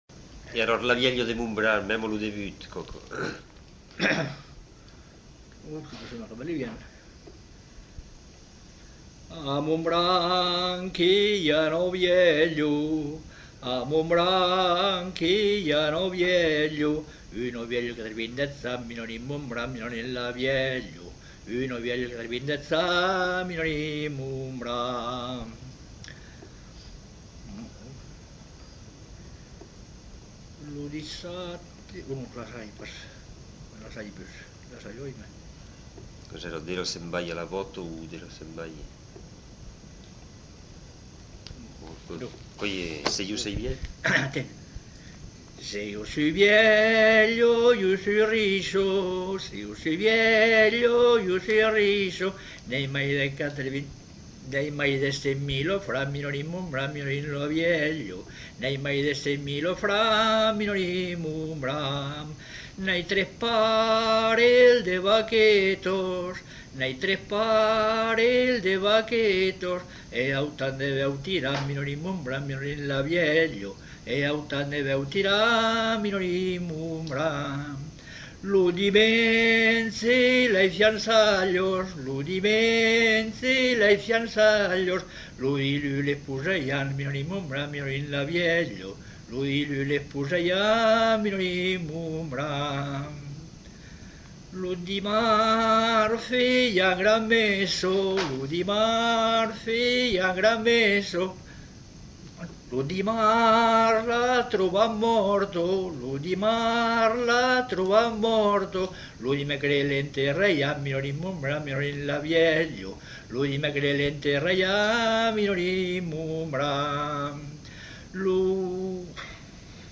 Genre : chant
Effectif : 1
Type de voix : voix d'homme
Production du son : chanté
Ecouter-voir : archives sonores en ligne